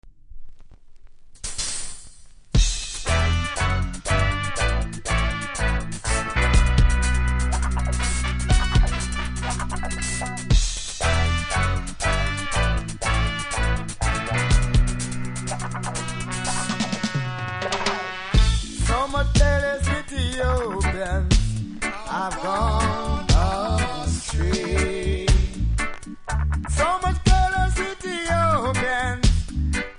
REGGAE 80'S
多少うすキズありますが音は良好なので試聴で確認下さい。